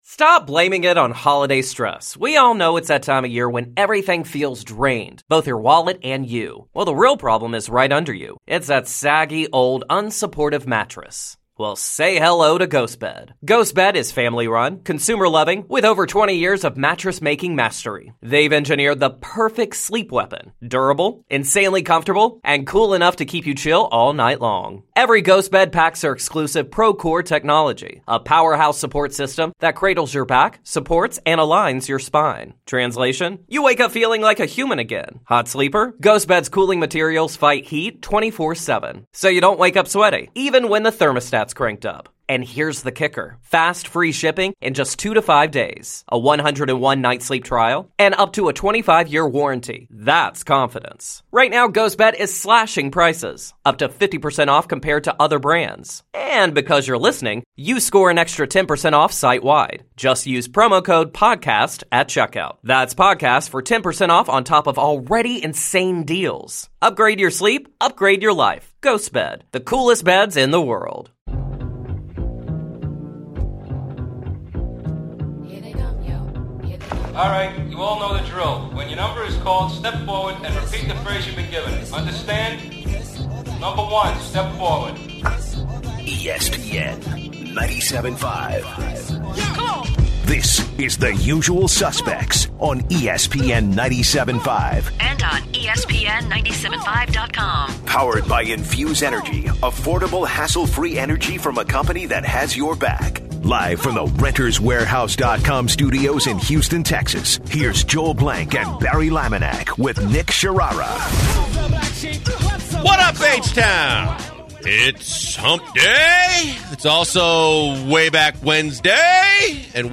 In hour one, the guys kick-off with way back Wednesday 1980's edition and debate if 80's music was the best of all time. Also, the guys go ov3er the songs that are most played in sporting events and clubs. Retired NBA Basketball player Muggsy Bogues joins the show to speak about the MVP race, his early baskeyball days, and today's NBA game.